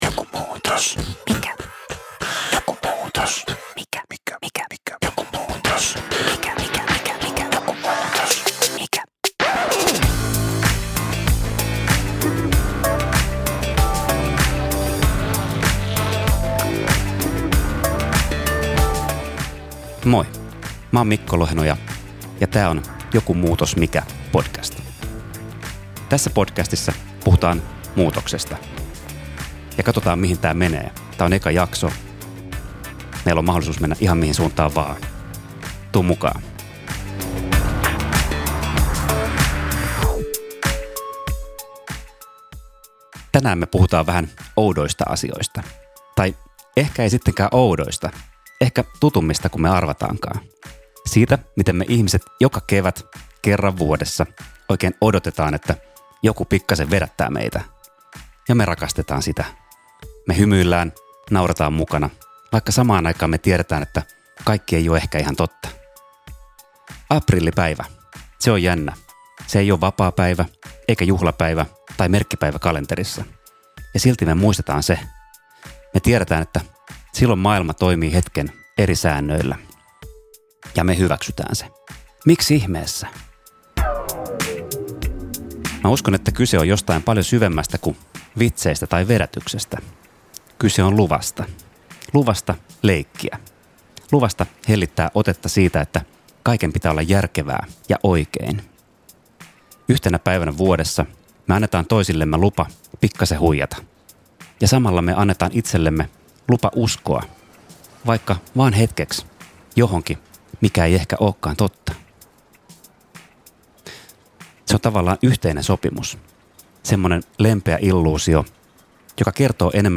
Sarja tarinoita, oivalluksia ja illuusioita muutoksesta. Yksi ääni, monta kerrosta.